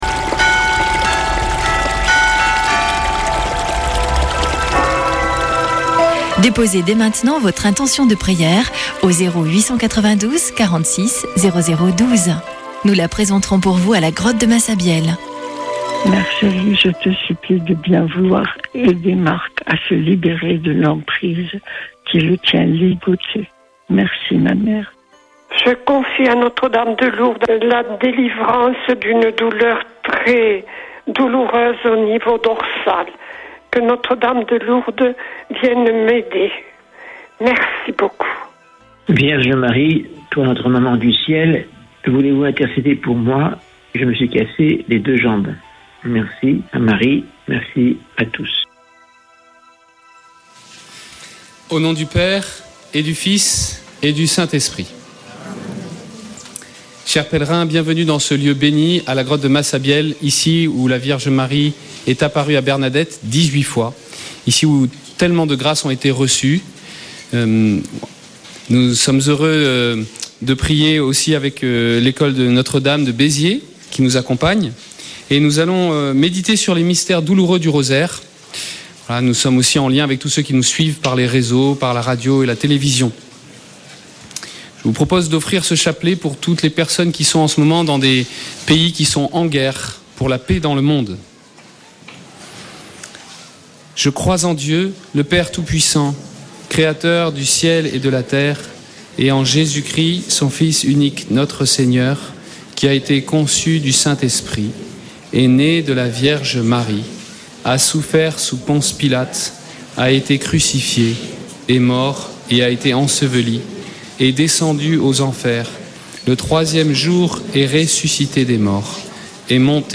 Chapelet de Lourdes du 17 avr.
Une émission présentée par Chapelains de Lourdes